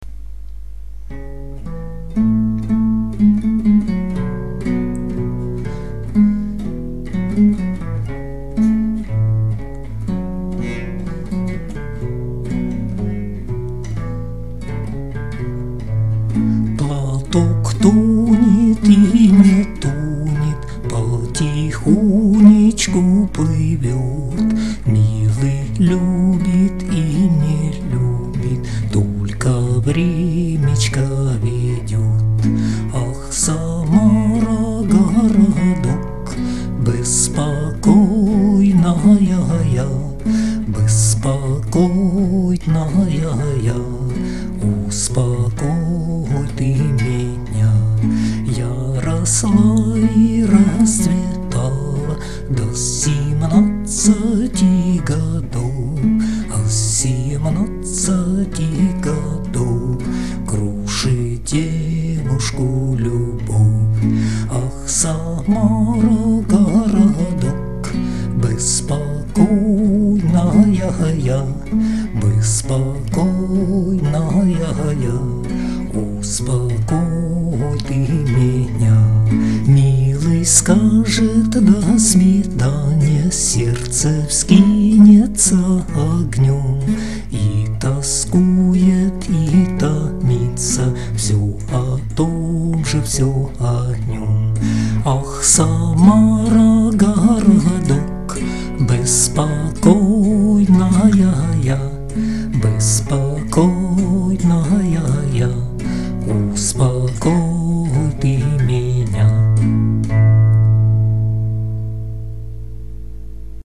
../icons/bagulnik.jpg   Русская народная песня